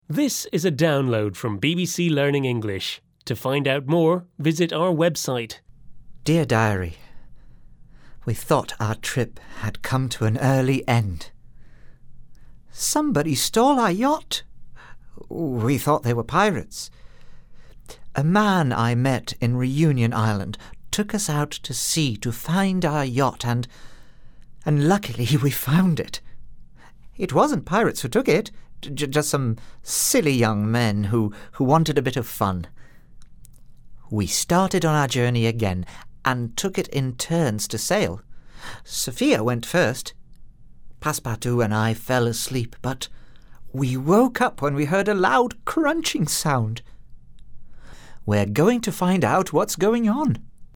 unit-6-4-1-u6_eltdrama_therace_audio_diary_download.mp3